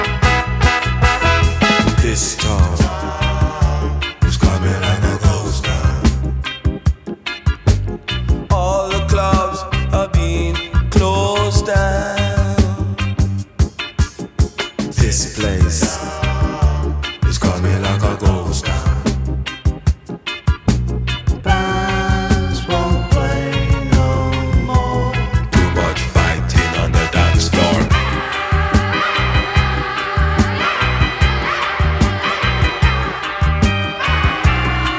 Жанр: Поп музыка / Альтернатива / Регги